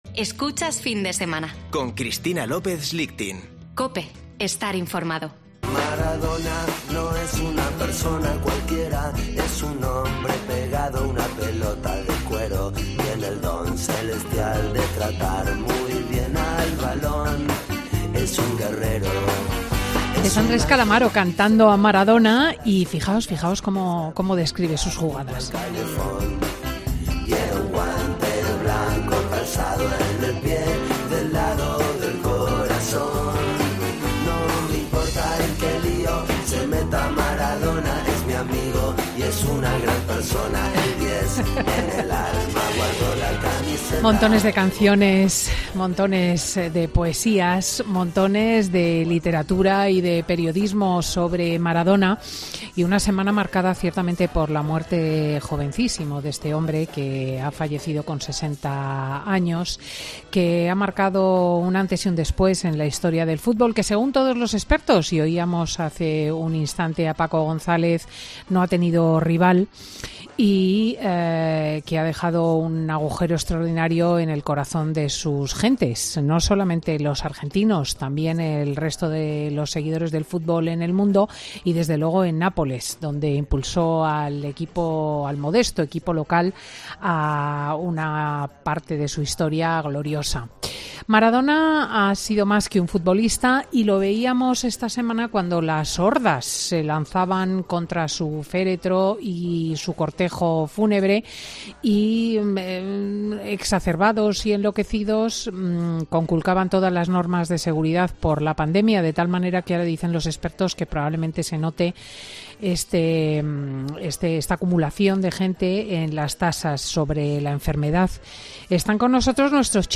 Tertulia de chicos: Maradona, más que un futbolista, un símbolo